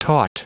Prononciation du mot taut en anglais (fichier audio)